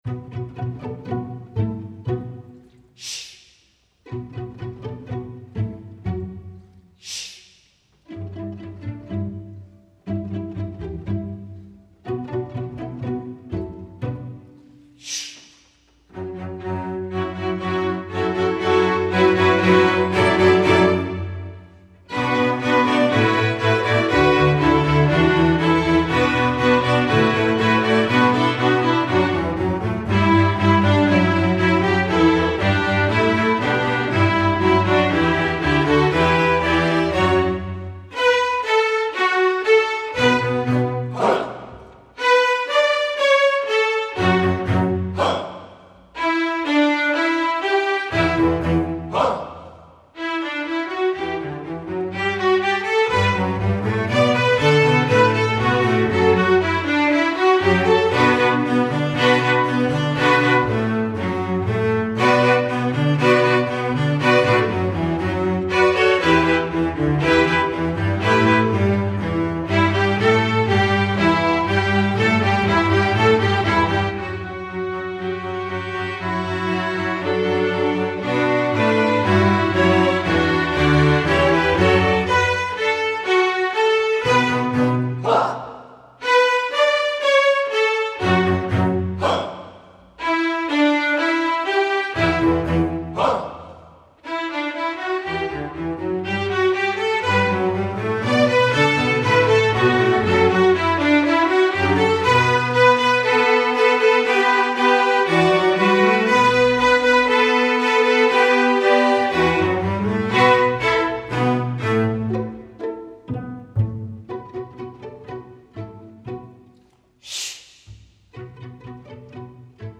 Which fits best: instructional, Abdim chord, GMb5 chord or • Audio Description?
instructional